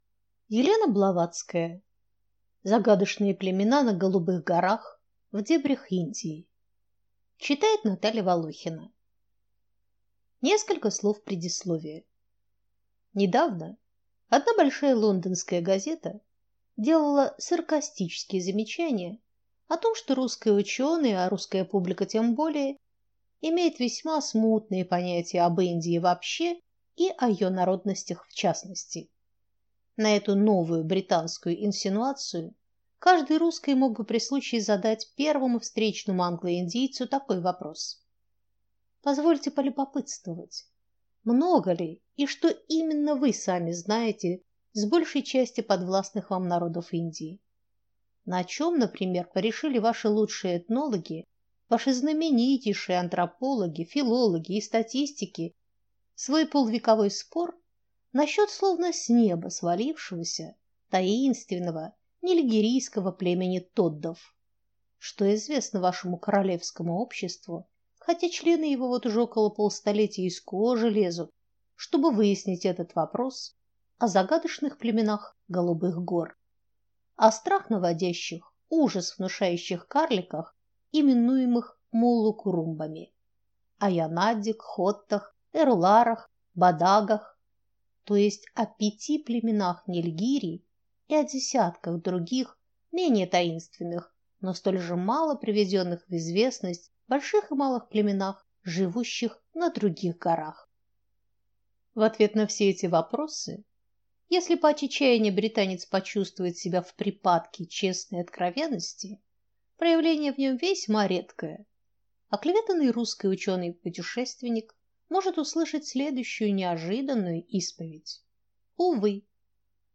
Аудиокнига Загадочные племена на «Голубых горах» | Библиотека аудиокниг